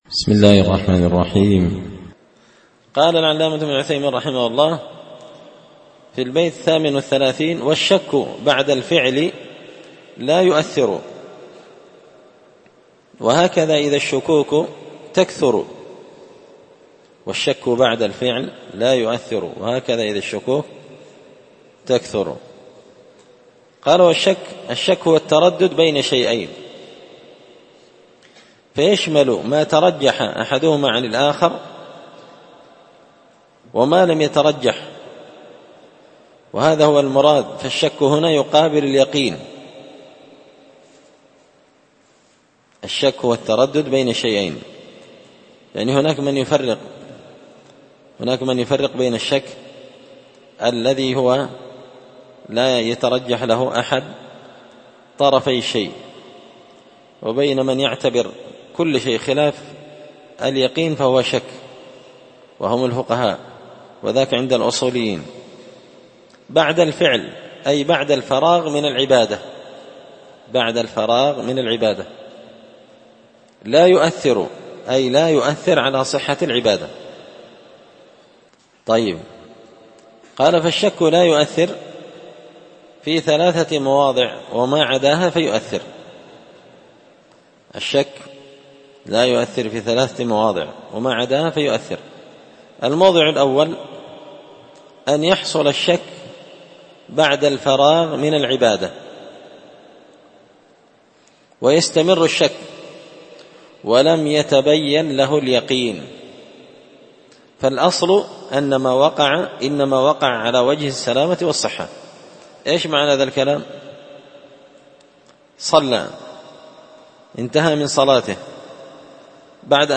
تسهيل الوصول إلى فهم منظومة القواعد والأصول ـ الدرس 22
دار الحديث بمسجد الفرقان ـ قشن ـ المهرة ـ اليمن